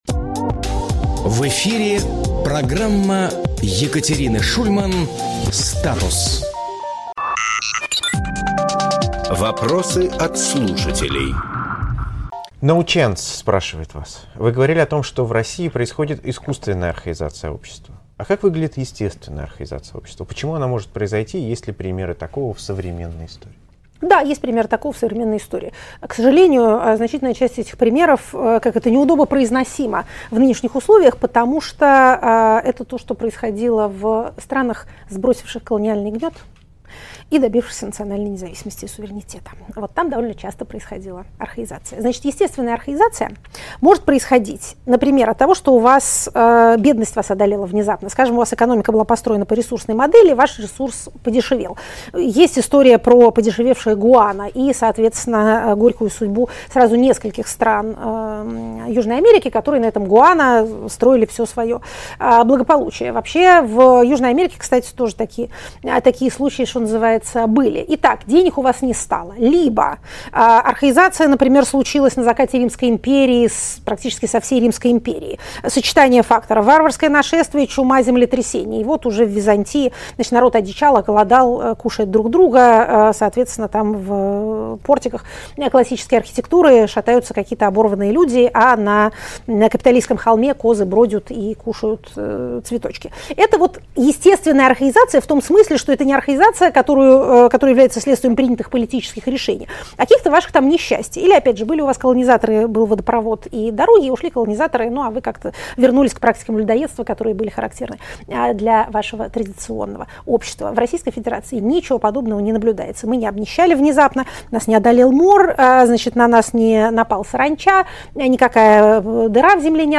Екатерина Шульманполитолог
Фрагмент эфира от 22.07.2025